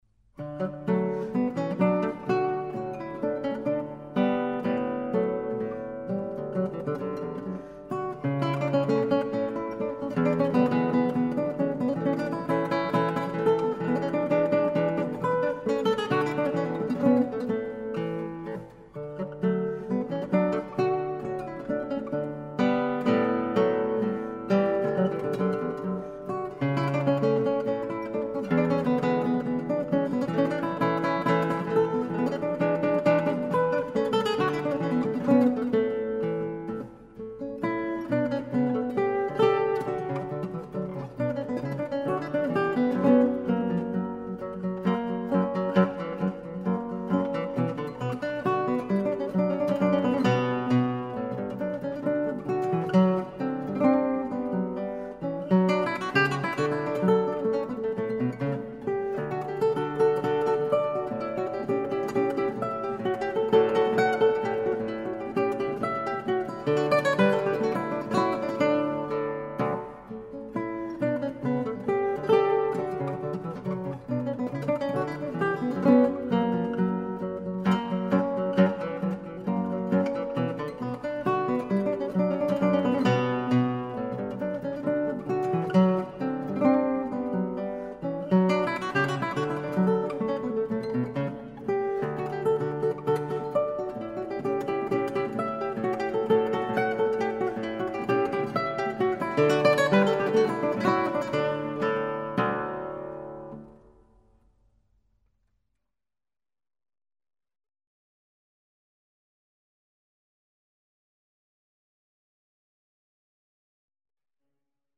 Alexandre Lagoya, Guitar